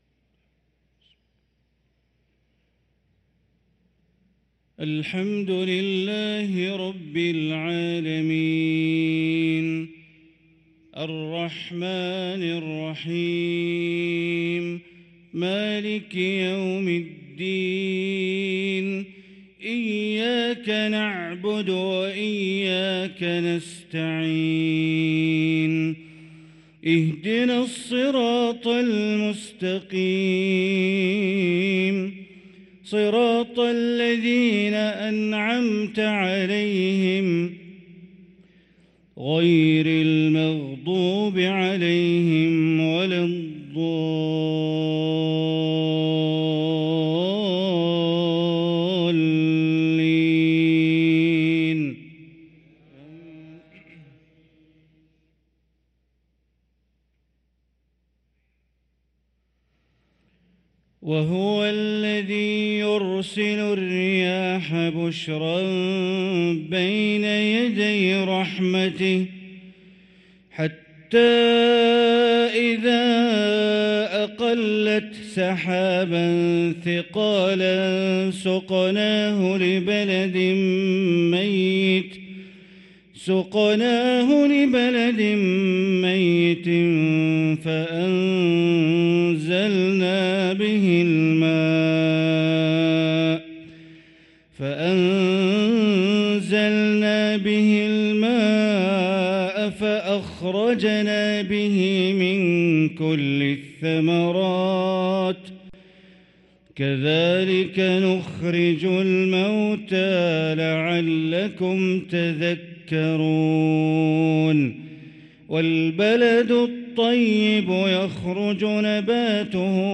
صلاة المغرب للقارئ بندر بليلة 13 ربيع الآخر 1445 هـ